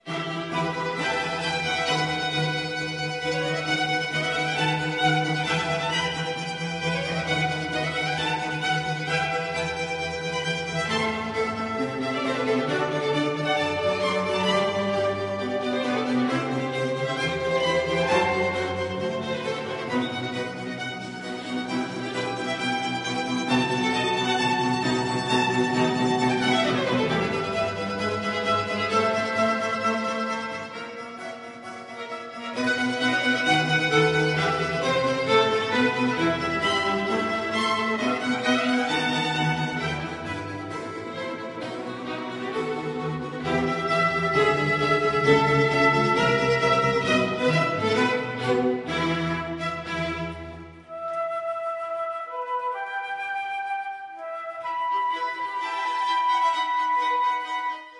Concert for flute and orchestra in e minor - 1. Allegro con brio   ( Vox Bohemica live concert 26.5.2005 Cesky Brod)